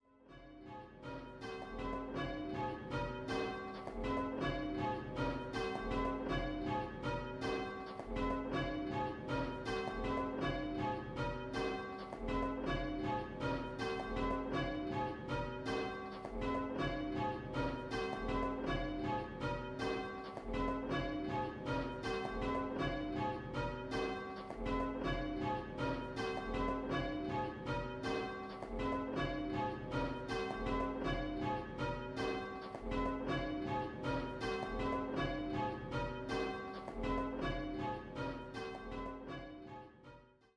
5 & 6 Bell Game